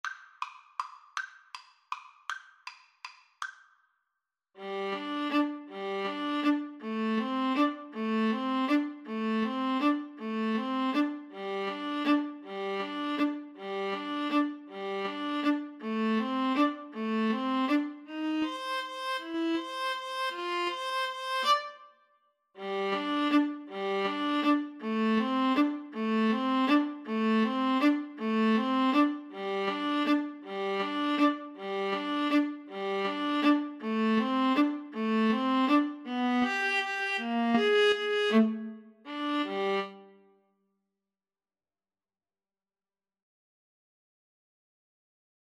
3/4 (View more 3/4 Music)
Allegro grazioso =160 (View more music marked Allegro)
Classical (View more Classical Violin-Viola Duet Music)